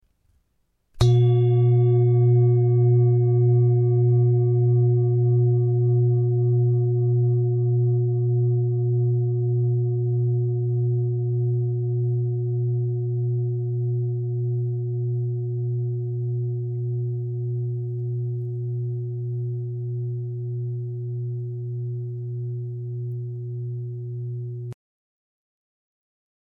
Indische Bengalen Klangschale - CERES
Grundton: 118,51 Hz
1. Oberton: 360,86 Hz
PLANETENTON CERES TON AIS